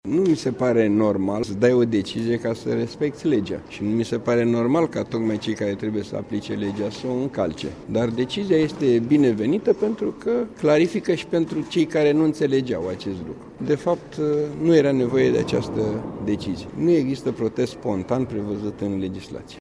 Vicepreşedintele Camerei Deputaţilor, Eugen Nicolicea, consideră că nu era necesar ca Instanţa Supremă să reglementeze organizarea adunărilor publice, întrucât acest lucru era prevăzut în lege. El a adăugat că respectiva decizie aduce, totuşi, unele clarificări în ceea ce priveşte aplicarea legislaţiei în vigoare:
Eugen-Nicolicea.mp3